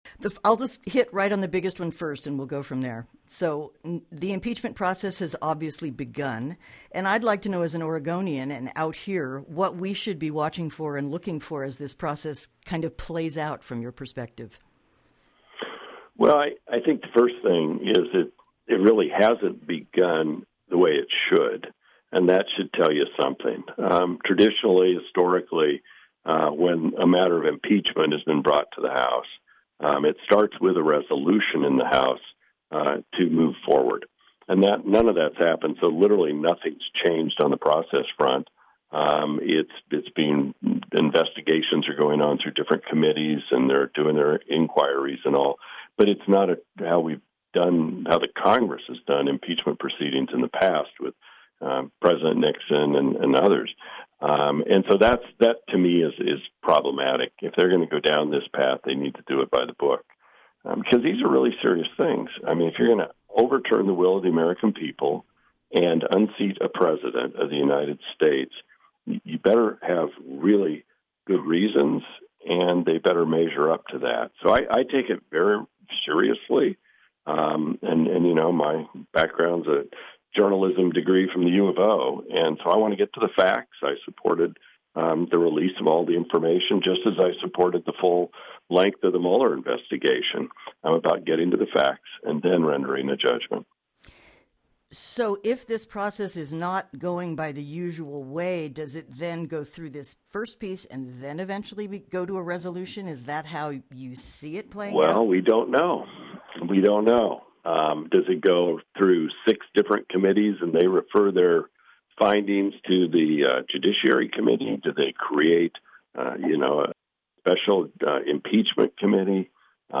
Waldeninterview_web.mp3